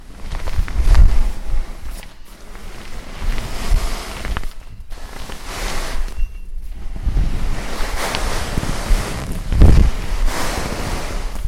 Geräusche zu den Klangexperimenten